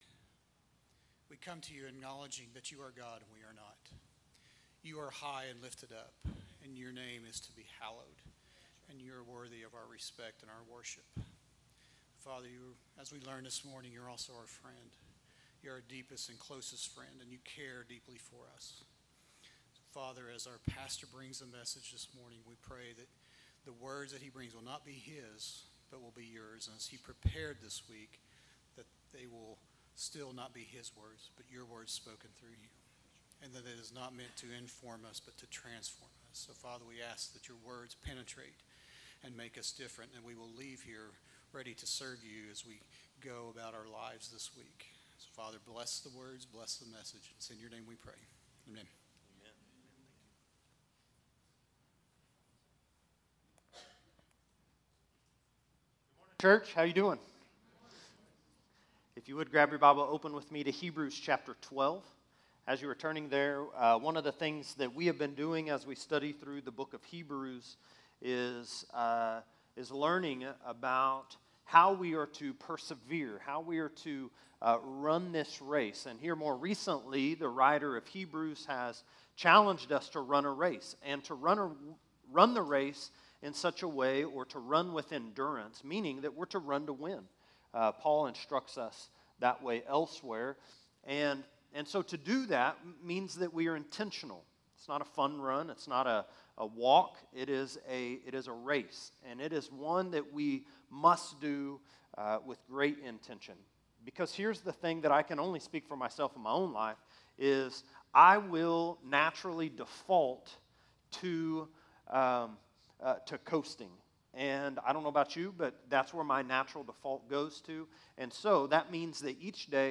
A message from the series "Hebrews." Jesus is Greater than the High Priest Hebrews 5:1-10 Series: Hebrews [CCLI #: 58367]